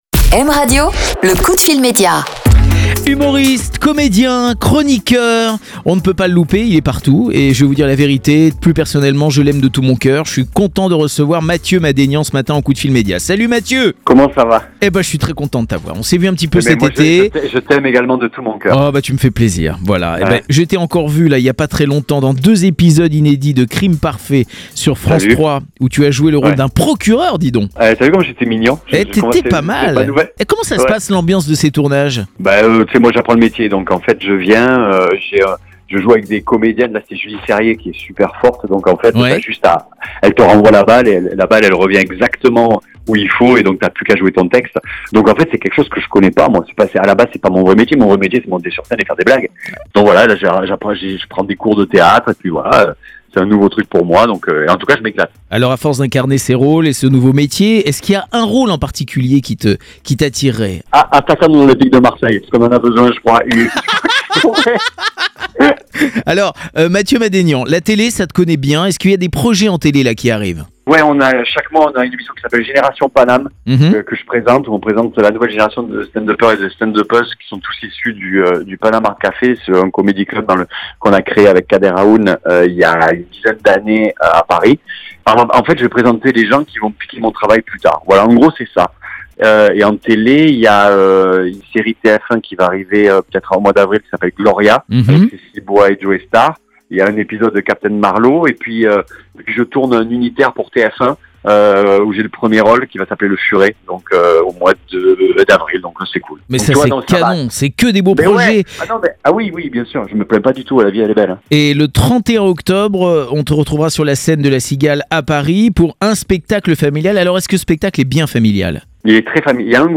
Ce matin Jerôme Anthony recevait Mathieu Madenian pour parler de son actualité